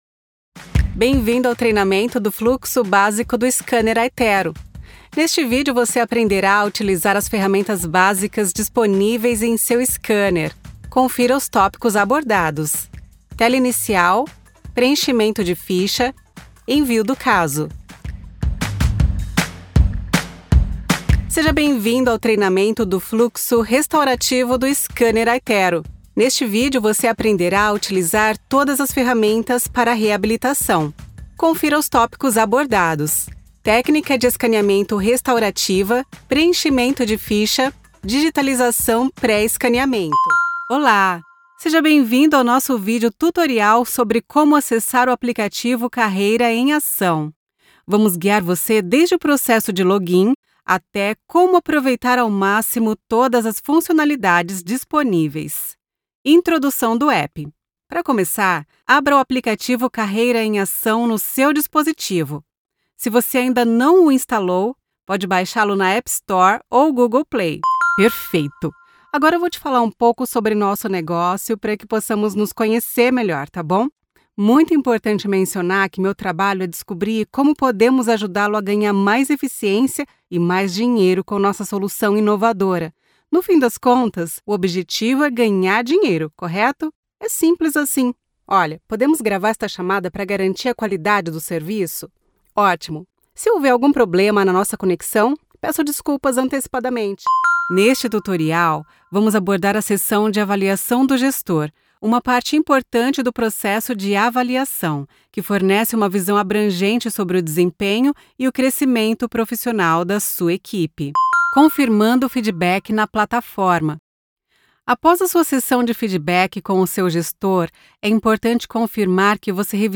Never any Artificial Voices used, unlike other sites.
Female
E-Learning